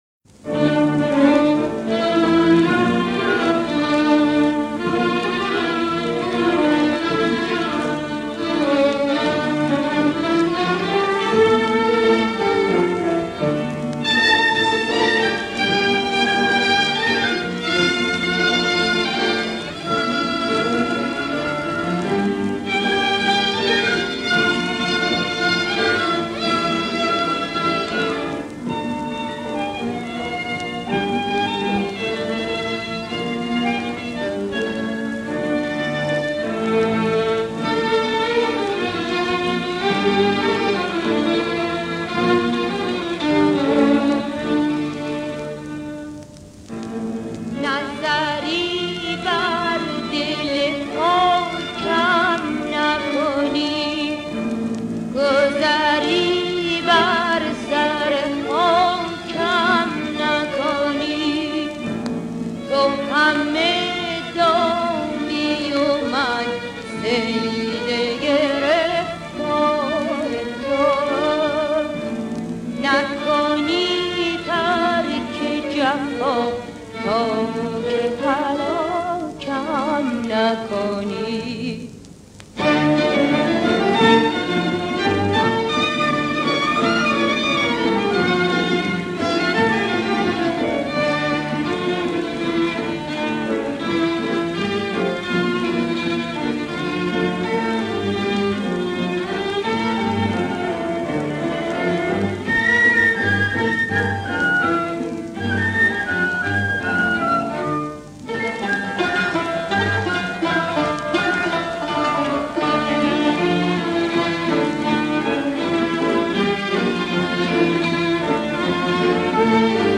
در مایه: بیات اصفهان